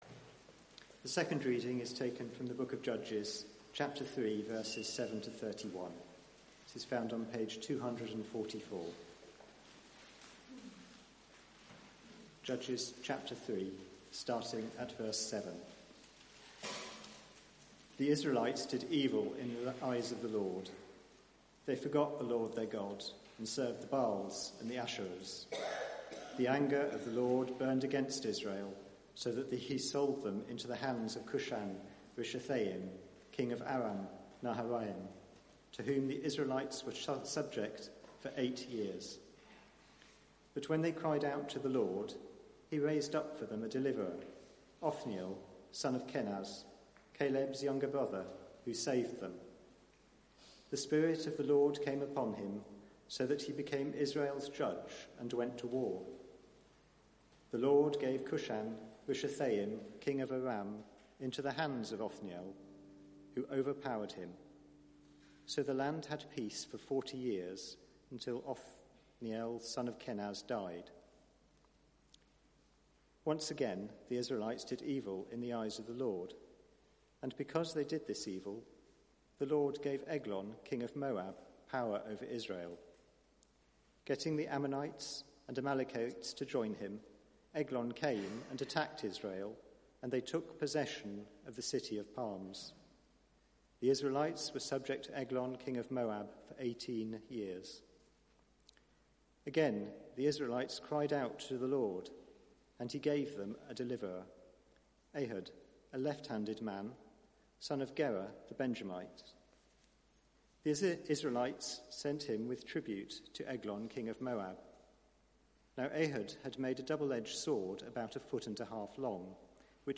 Media for 6:30pm Service on Sun 13th Oct 2019
Theme: Othniel and Ehud: the First Deliverers Sermon